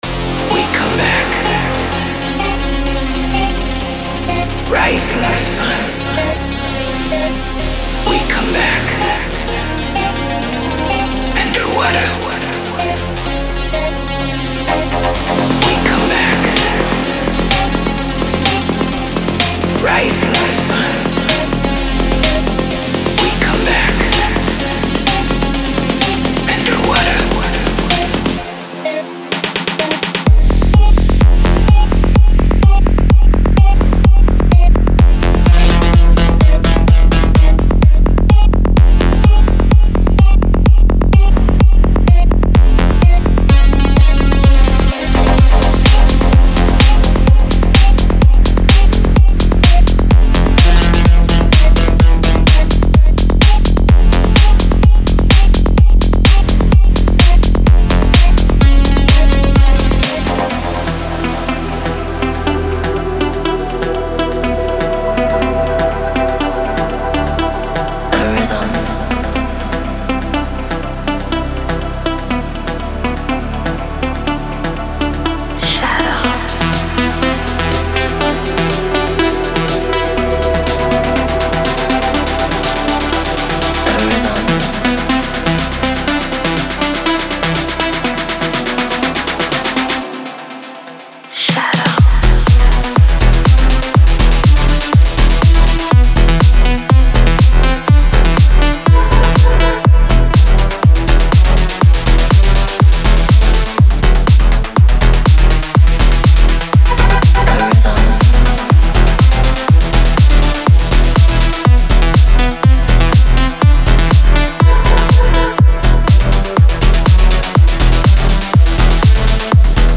Genre:Melodic Techno
デモサウンドはコチラ↓
29 Vocal Loops
91 Synth Loops
19 Drum Loops
42 Fx One-Shots